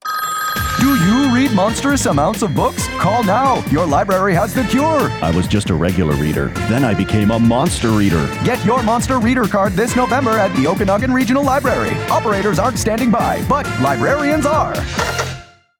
We are also sharing three brand new radio ads from our Monster Reader campaign.
This ad takes inspiration from a vintage infomercial.
Okanagan-Regional-Library-Monster-Hotline-Nov2025.mp3